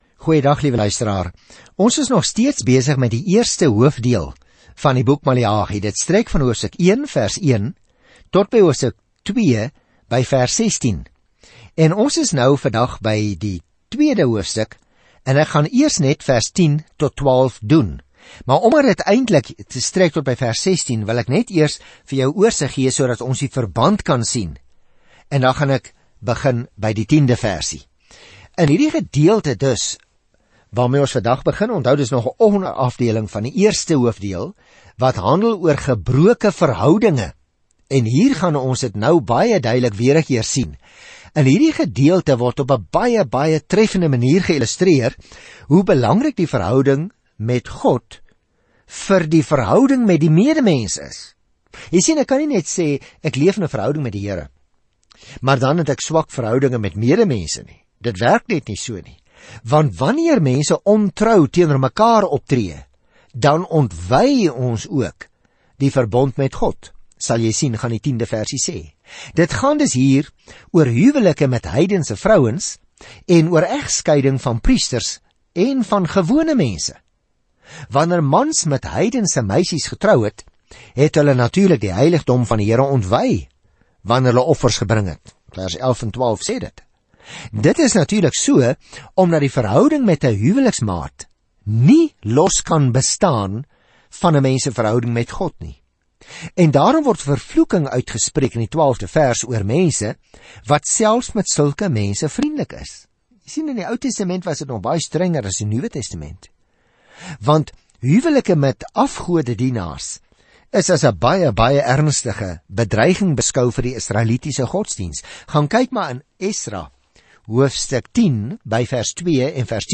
Skrif MALEAGI 2:10-12 Dag 6 Begin met hierdie leesplan Dag 8 Aangaande hierdie leesplan Maleagi herinner ’n ontkoppelde Israel daaraan dat hy ’n boodskap van God het voordat hulle ’n lang stilte verduur – wat sal eindig wanneer Jesus Christus die verhoog betree. Reis daagliks deur Maleagi terwyl jy na die oudiostudie luister en uitgesoekte verse uit God se woord lees.